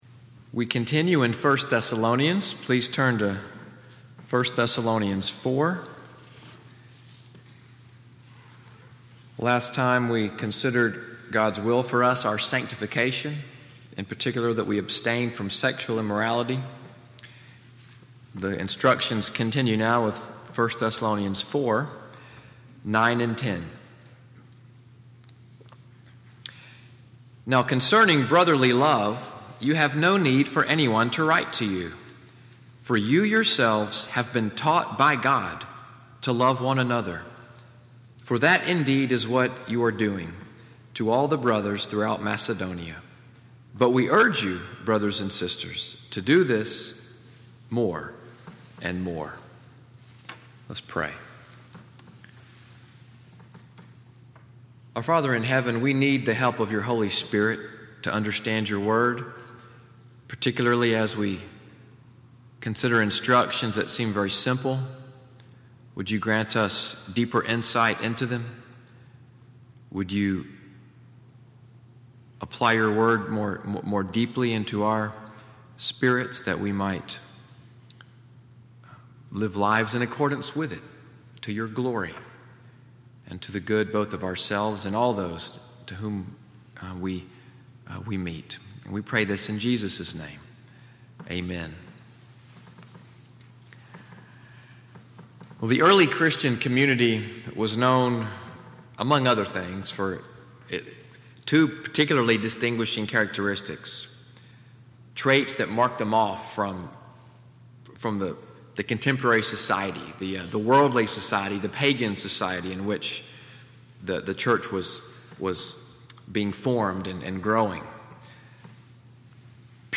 Evening Worship at NCPC-Selma, audio of the sermon, “Love for the Saints,” November 26, 2017.